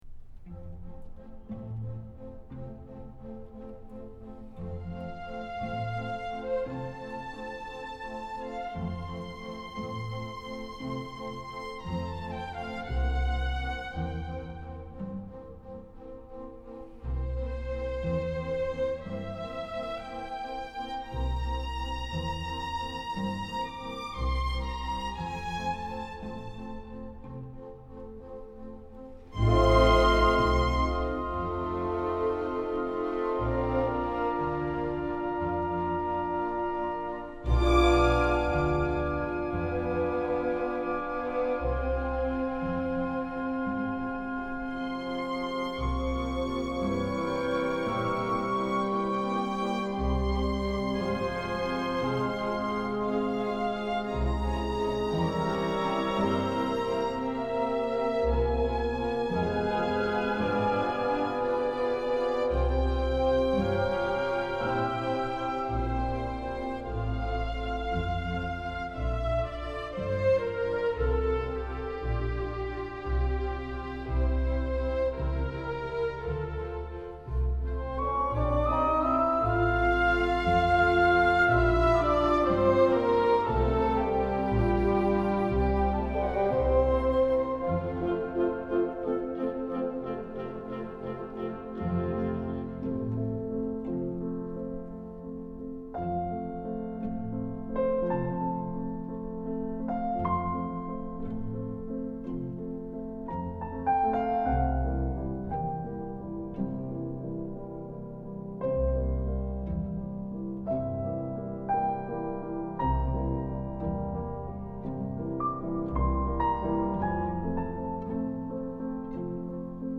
音場層次分明、音質真實透明。